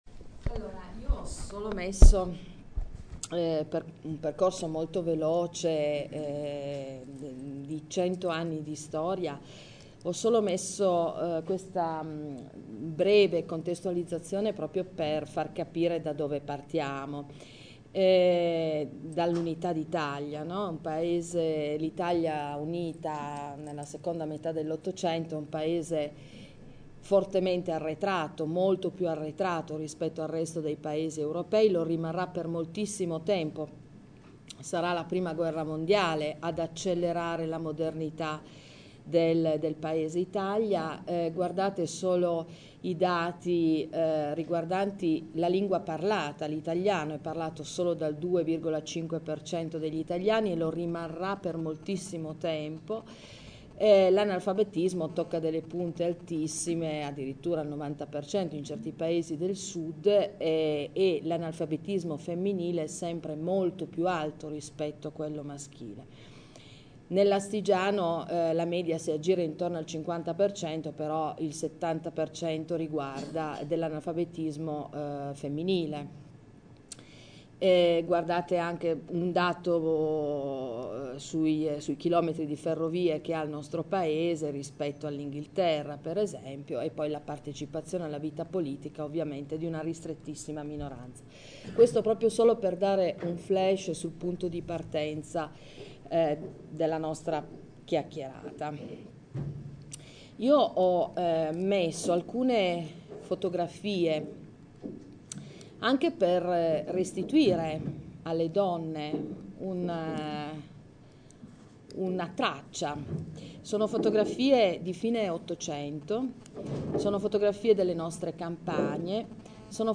L’incontro si è tenuto presso il Museo Arti e Mestieri di un Tempo di Cisterna d’Asti sabato 29 novembre 2014